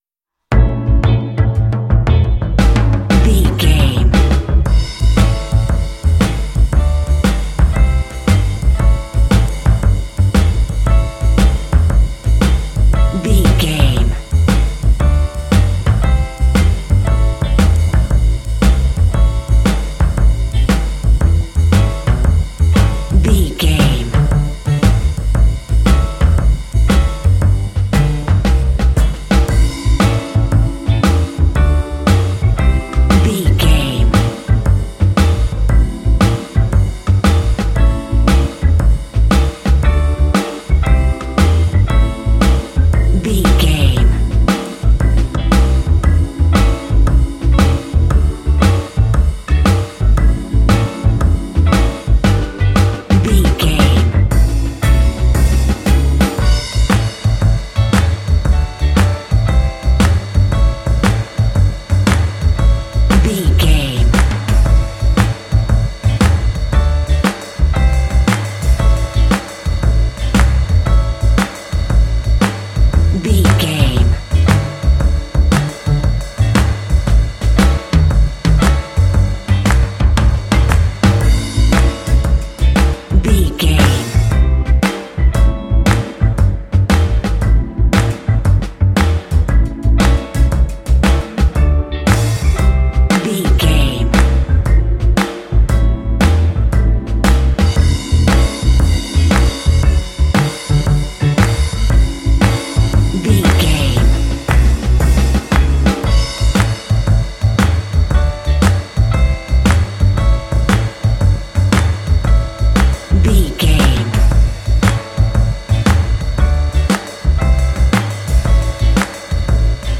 This 12-bar blues track comes with several layers
Uplifting
Aeolian/Minor
groovy
melancholy
bouncy
electric guitar
drums
double bass
electric organ
blues